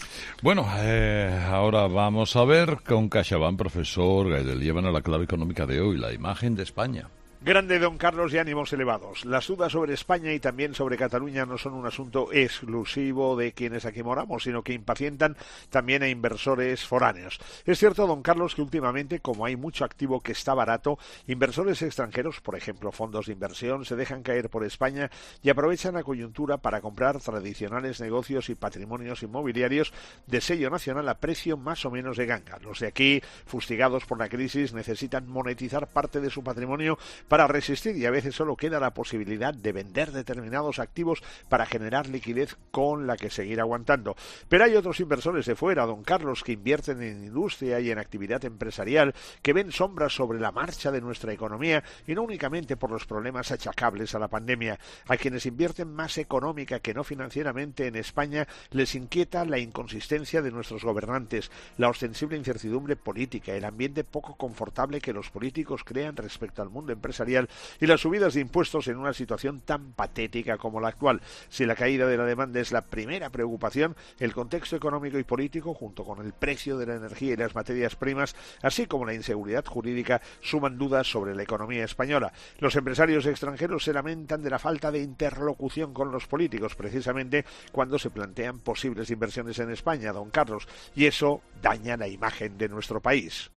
La 'Mirada económica' del profesor en 'Herrera en COPE' de este viernes 14 de mayo 2021